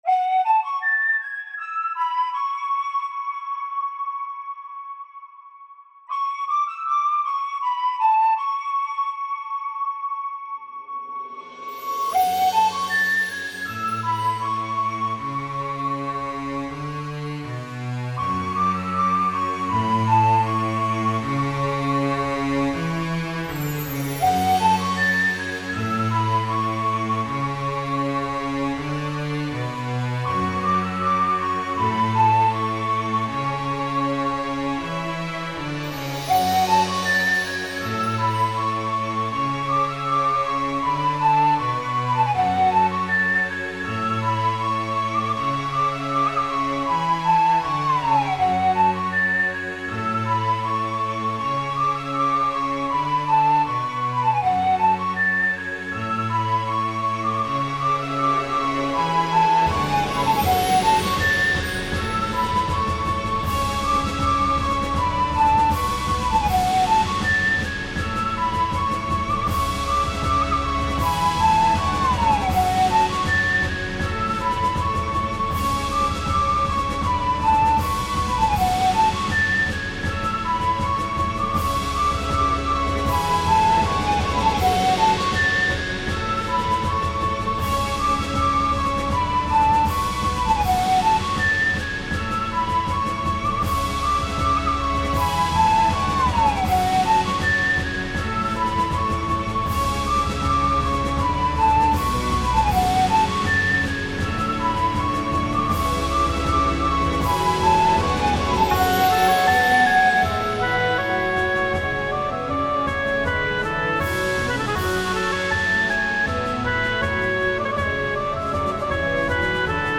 Music / Classical